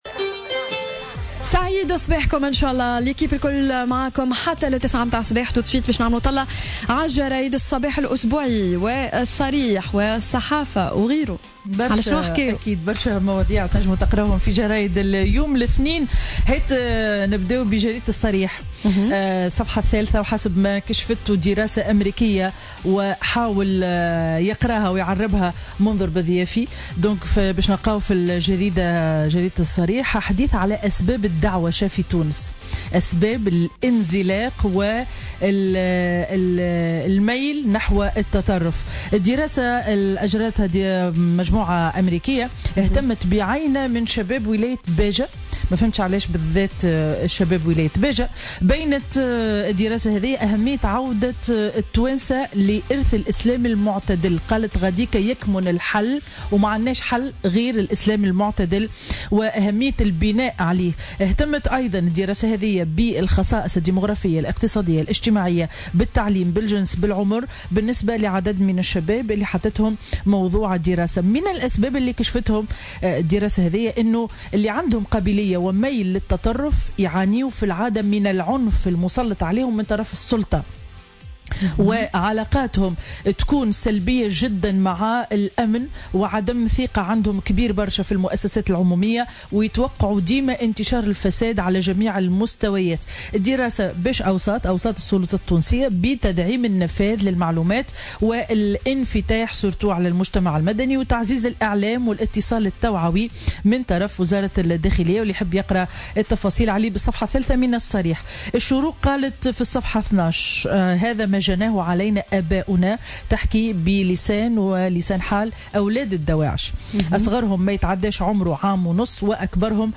Revue de presse du lundi 17 Avril 2017